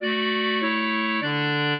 clarinet